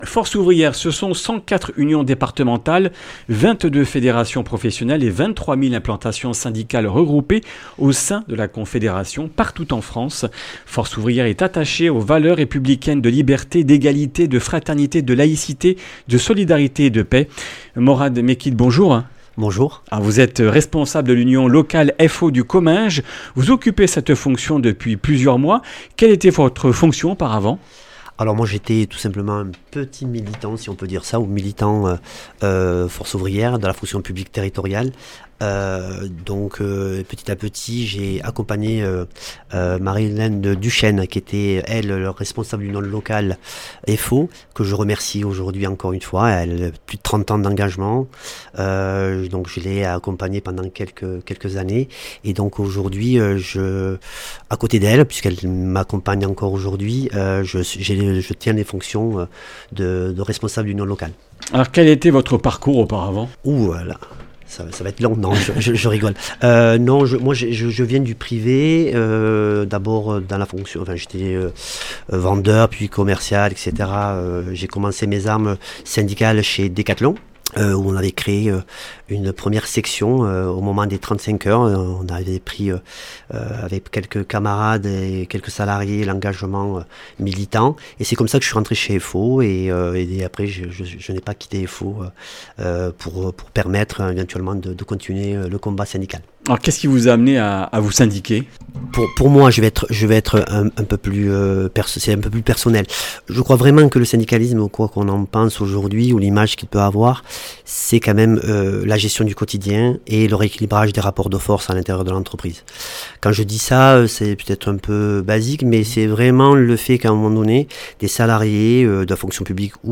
Comminges Interviews du 20 oct.